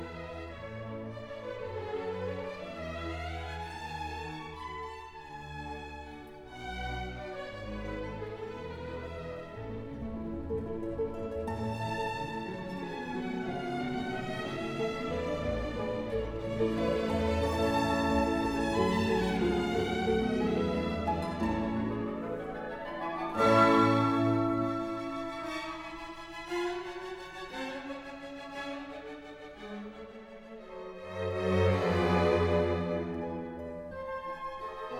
# Classical